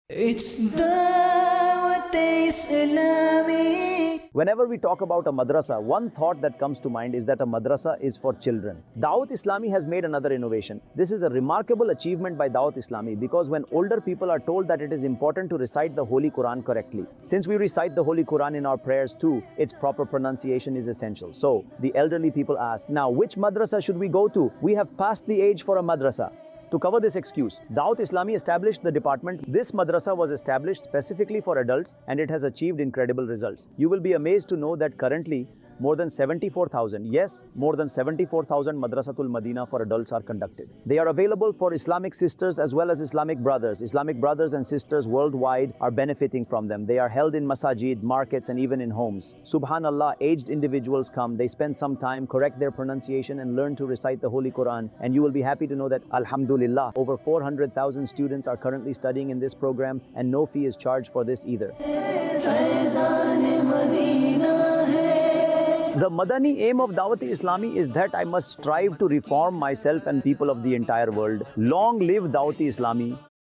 Madrasa Tul Madinah For Adults | Department of Dawateislami | Documentary 2025 | AI Generated Audio Mar 22, 2025 MP3 MP4 MP3 Share مدرسۃ المدینہ بالغان | شعبہِ دعوت اسلامی | ڈاکیومینٹری 2025 | اے آئی جنریٹڈ آڈیو